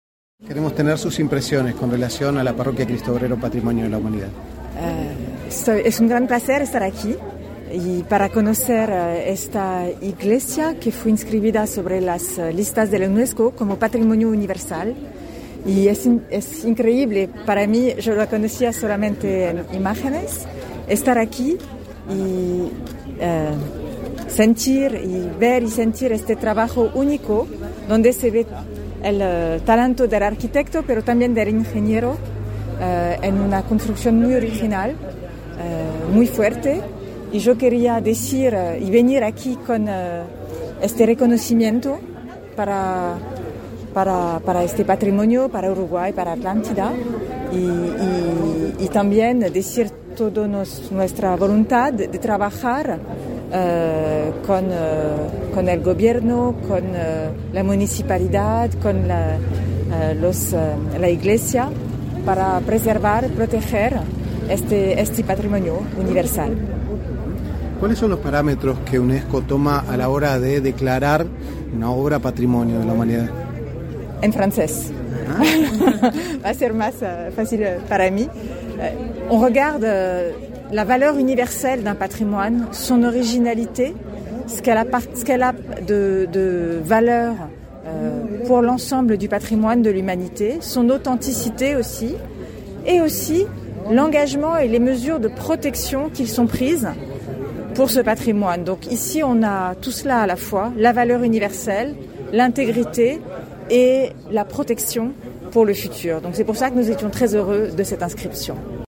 Declaraciones a la prensa de la directora general de Unesco, Audrey Azoulay
Declaraciones a la prensa de la directora general de Unesco, Audrey Azoulay 03/05/2022 Compartir Facebook X Copiar enlace WhatsApp LinkedIn Este 3 de mayo se realizó la ceremonia de entrega del título de inscripción en la Lista de Patrimonio Mundial de la Iglesia Cristo Obrero, proyectada por el Ingeniero Eladio Dieste. Tras el evento, la respresentante de Unesco efectuó declaraciones a la prensa.